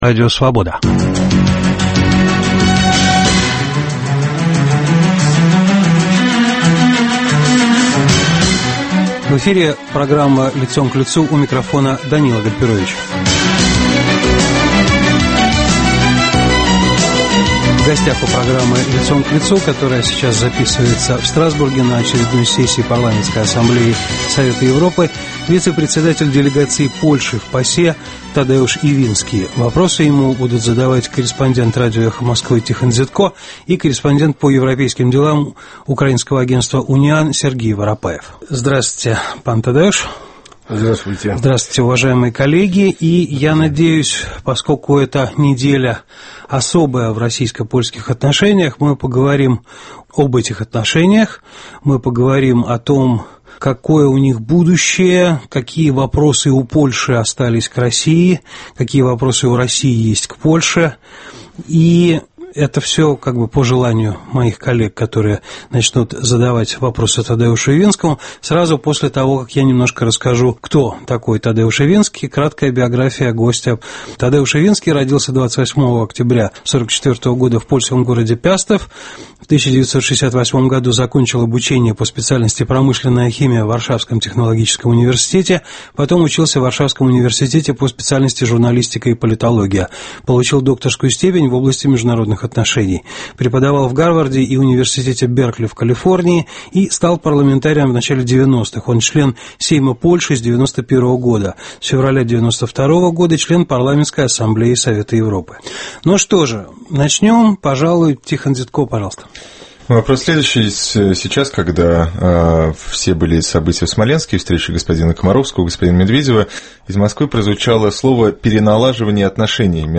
В программе - заместитель руководителя делегации Польши в Парламентской ассамблее Совета Европы Тадеуш Ивинский.
Программа записана на сессии ПАСЕ в Страсбурге.